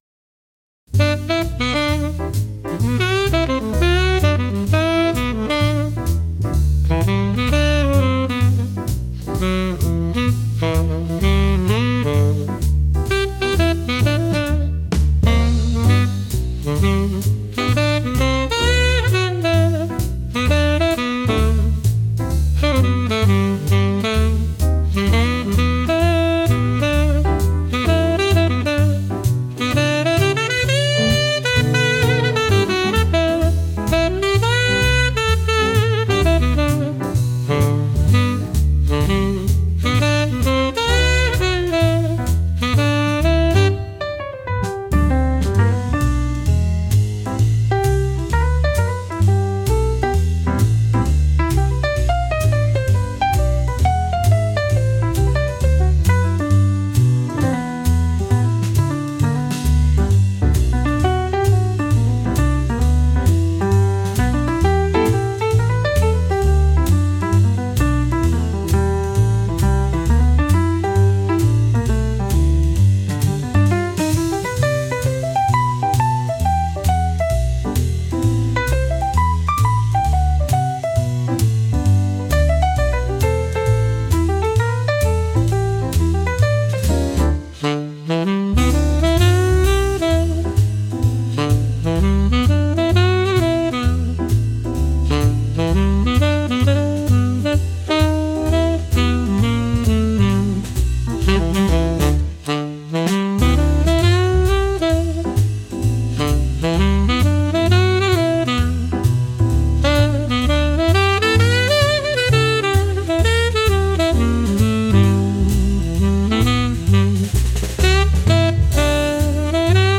ホテルで使える落ち着いたジャズです。